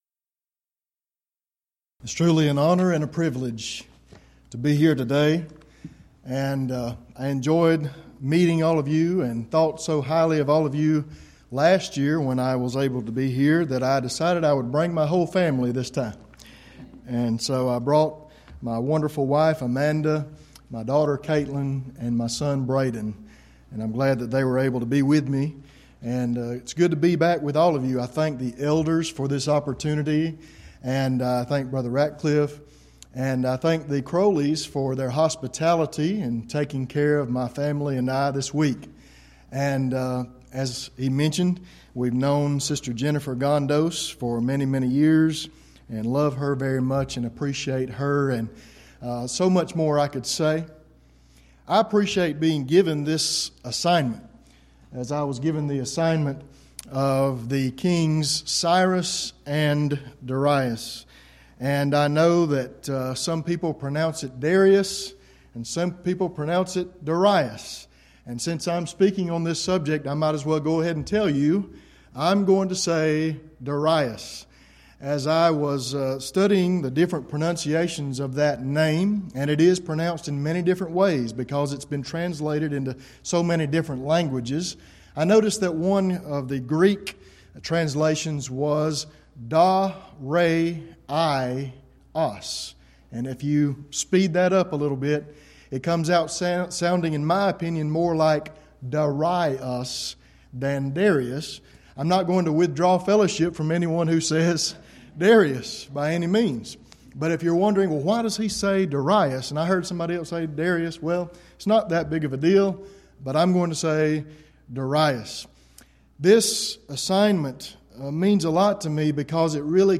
11th Annual Schertz Lectures
lecture